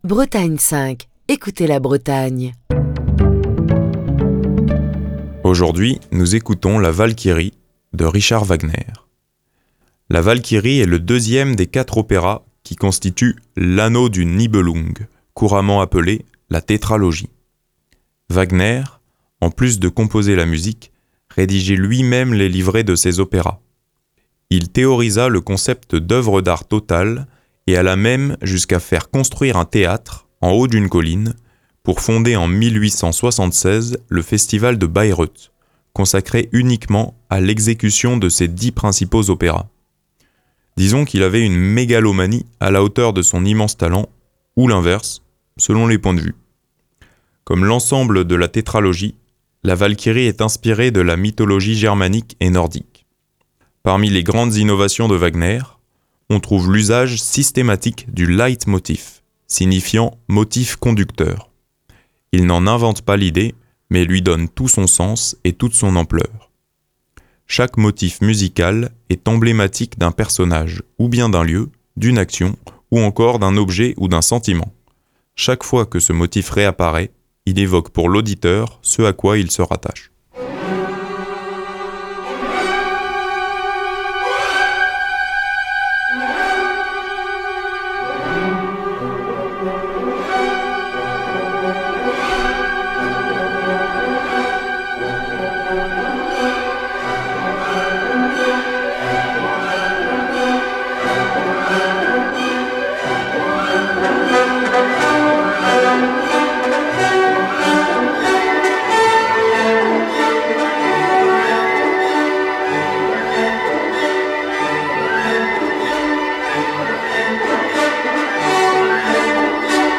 Absolument fulgurante et épique